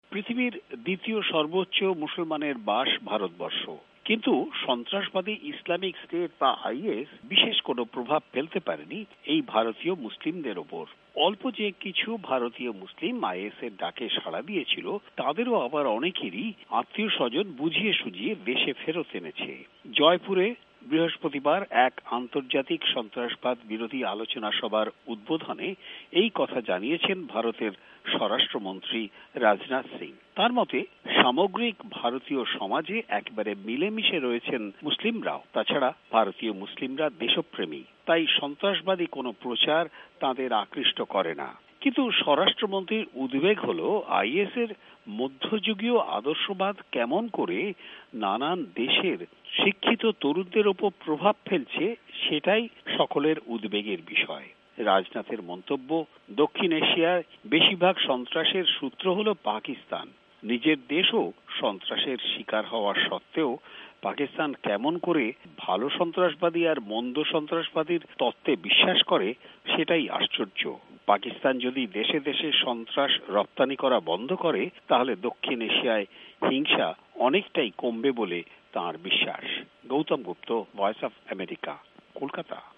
ভারতের স্বরাষ্ট্রমন্ত্রী রাজনাথ সিং সম্প্রতি এক সেমিনারে বলেছেন যে ভারতীয় মুসলমানদের উপর বিশেষ কোন প্রভাব ফেলতে পারেনি স্বঘোষিত ইসলামি স্টেট নামের জঙ্গি সংগঠন। এ সম্পর্কে কোলকাতা থেকে বিস্তারিত বিশ্লেষণাত্মক প্রতিবেদন পাঠিয়েছেন